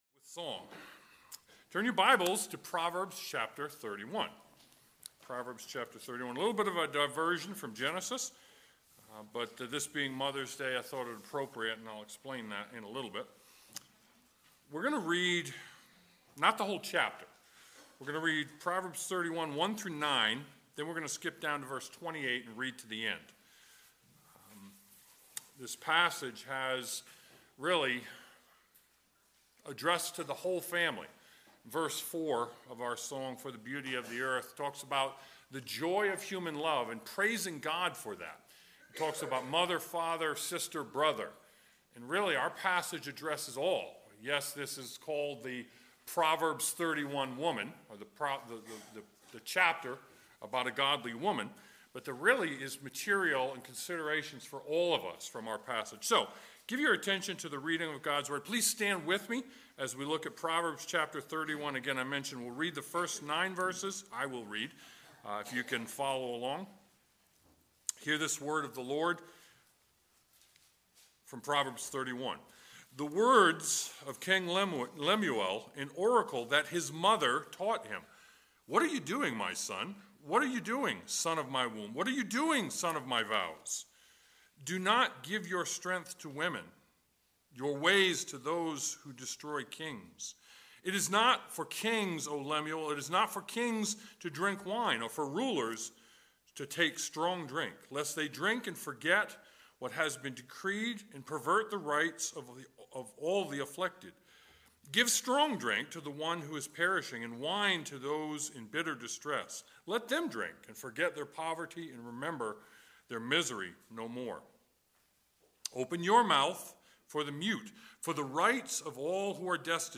Proverbs 31:1-31 Service Type: Sunday Morning Proverbs 31:1-31 The Proverbs 31 woman embraces her role as mother